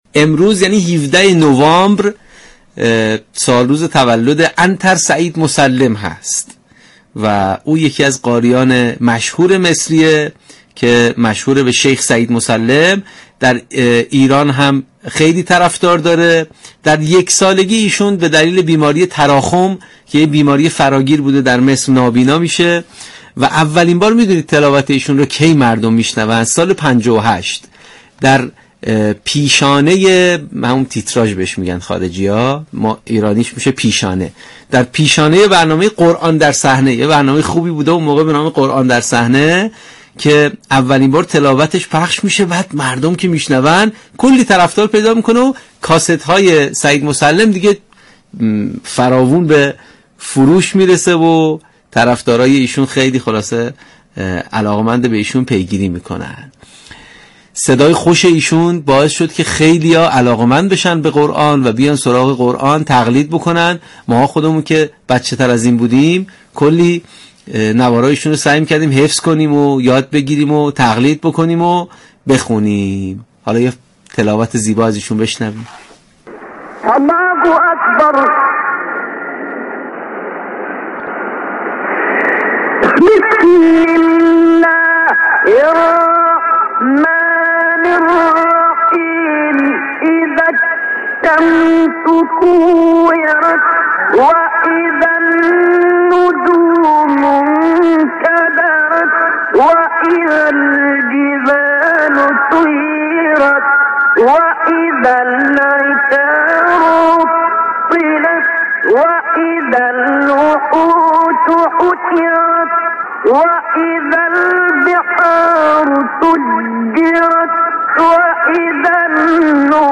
استاد عنتر سعید مسلم (زادۀ 17 نوامبر 1936 - درگذشتۀ 6 سپتامبر 2002) مشهور به شیخ سعید مسلم و در ایران معروف به شیخ بدوی یكی از قاریان نابینای مصری می‌باشد.
صدای زیبا و ملكوتی، سبك تلاوت بسیار تاثیرگذار و دلنشین و همچنین تسلط بالای ایشان بر قرائتهای مختلف، تاثیر زیادی بر مستمعین میگذاشت و خیلی زود آوازه تلاوتهای زیبای ایشان در همه جا فراگیر شد و از شهرهای مختلف از ایشان برای تلاوت دعوت میكردند و استقبال زیادی هم از تلاوتهای ایشان میشد.